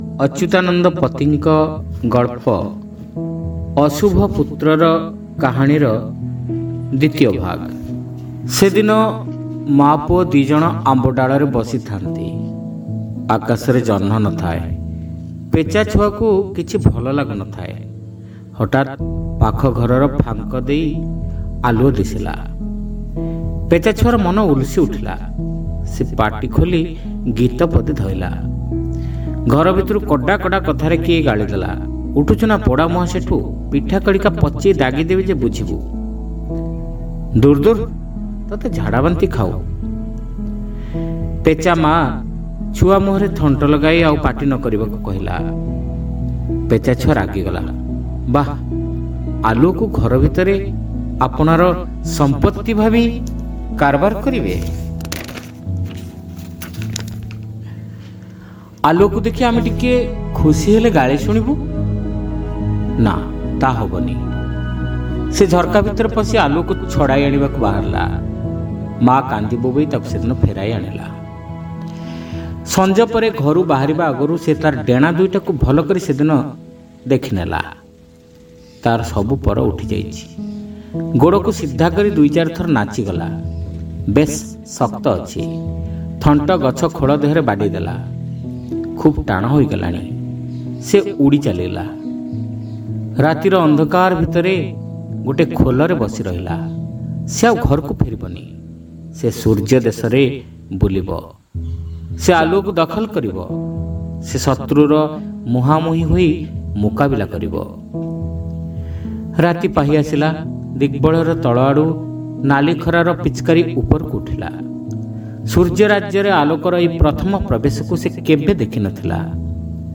ଶ୍ରାବ୍ୟ ଗଳ୍ପ : ଅଶୁଭ ପୁତ୍ରର କାହାଣୀ (ଦ୍ୱିତୀୟ ଭାଗ)